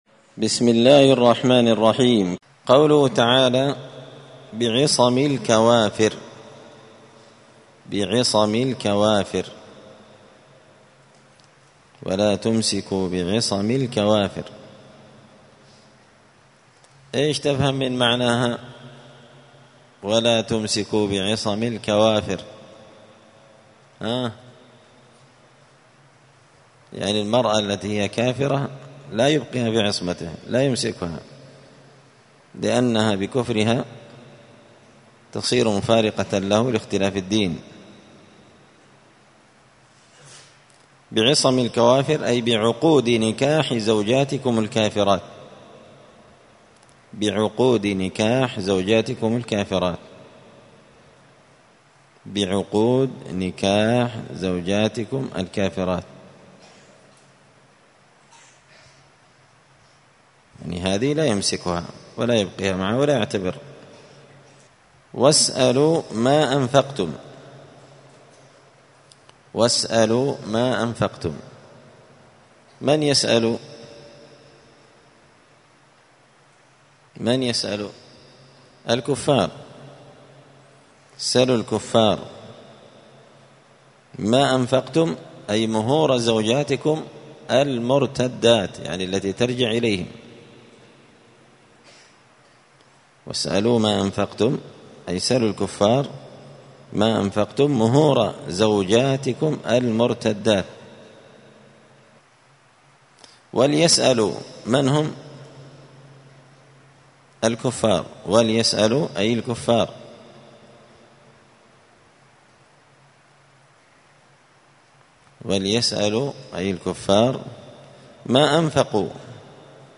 الأثنين 19 جمادى الآخرة 1445 هــــ | الدروس، دروس القران وعلومة، زبدة الأقوال في غريب كلام المتعال | شارك بتعليقك | 53 المشاهدات